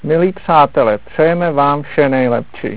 Zbytek nahrávky je zvukový záznam, zaznamenaný při rychlosti 16⅔ otáček za minutu s pozdravy obyvatel planety Země případným nálezcům v 55 jazycích
czech_voyager.wav